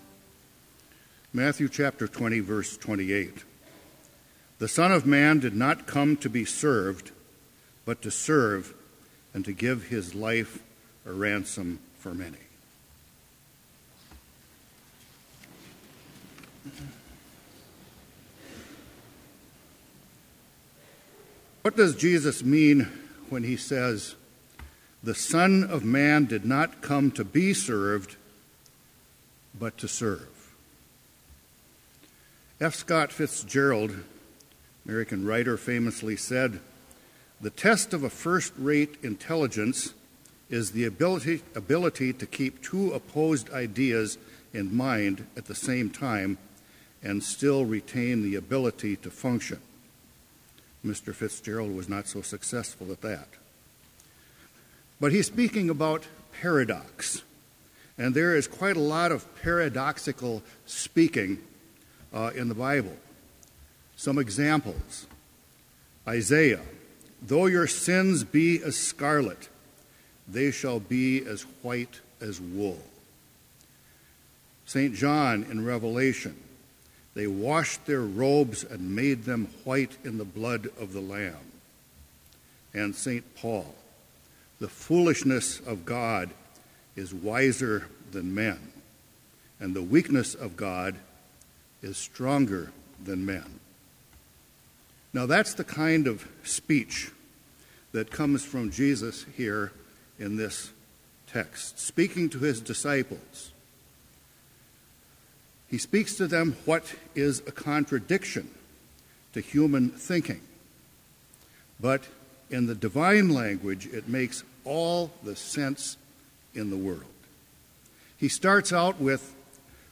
Complete Service
This Chapel Service was held in Trinity Chapel at Bethany Lutheran College on Wednesday, December 2, 2015, at 10 a.m. Page and hymn numbers are from the Evangelical Lutheran Hymnary.